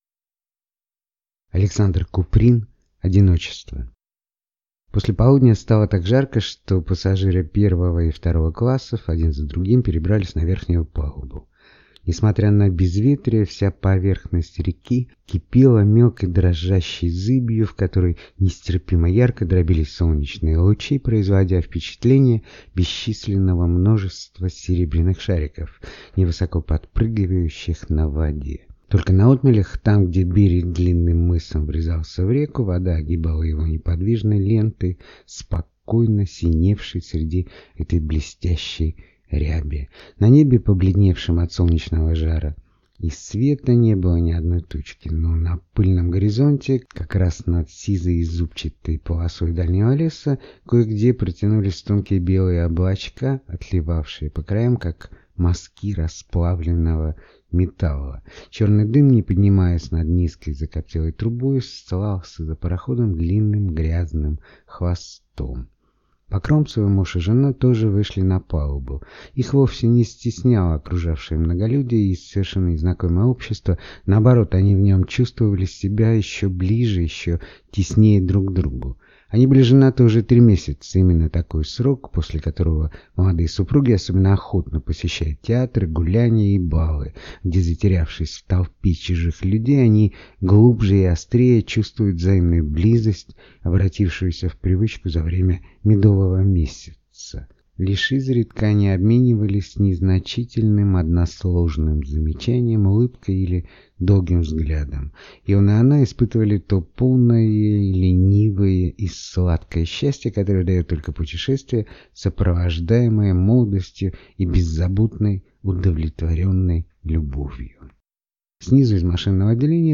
Аудиокнига Одиночество | Библиотека аудиокниг